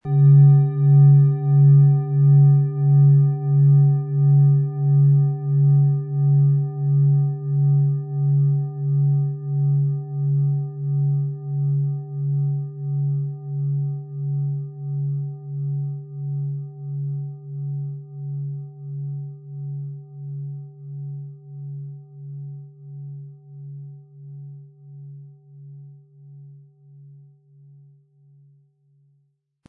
Planetenton 1
PlanetentonMerkur & Biorhythmus Geist (Höchster Ton)
MaterialBronze